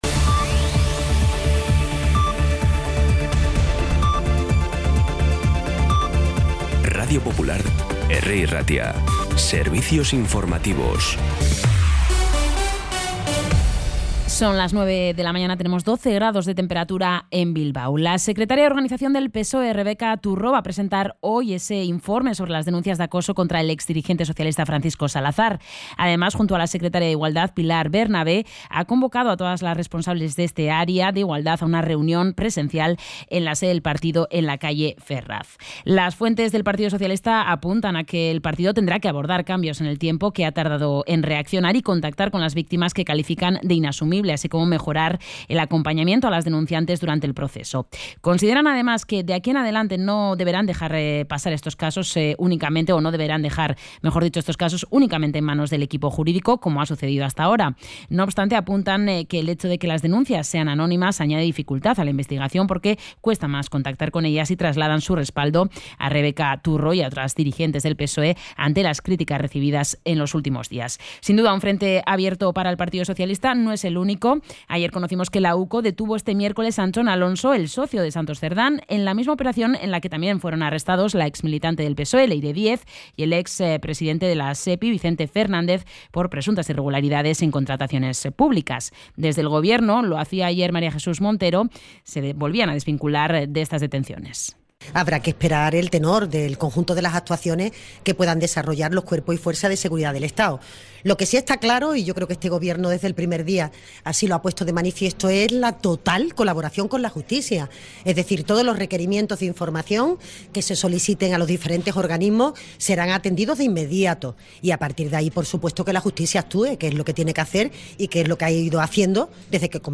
Las noticias de Bilbao y Bizkaia de las 9 , hoy 12 de diciembre
Los titulares actualizados con las voces del día. Bilbao, Bizkaia, comarcas, política, sociedad, cultura, sucesos, información de servicio público.